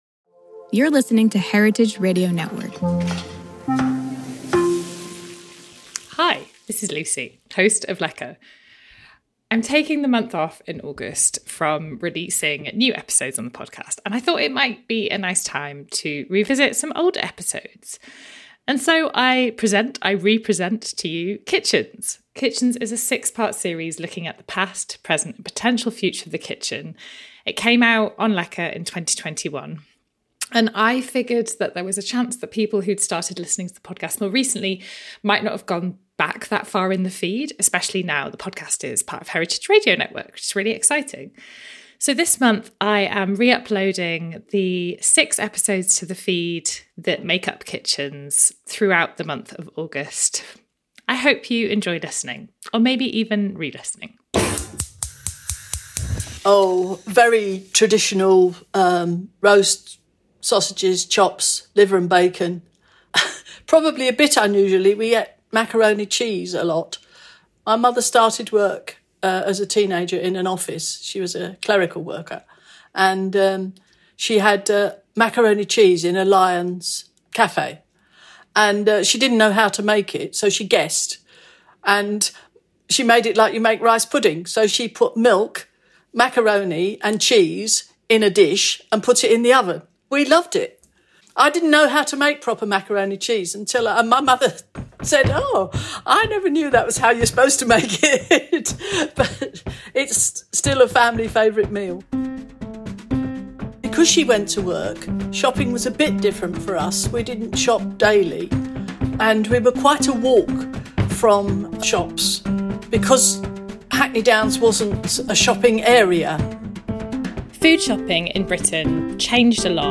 Original music was composed for the series